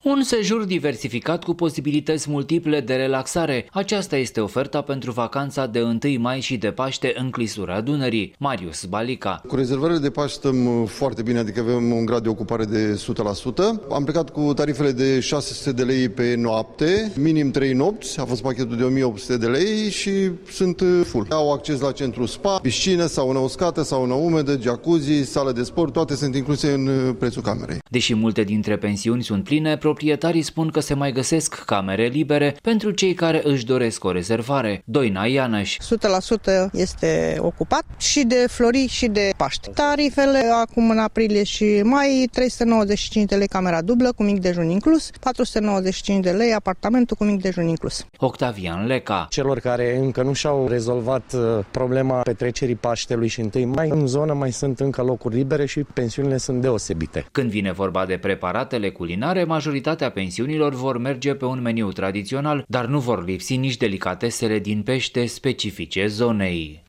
Corespondentul Radio România Actualităţi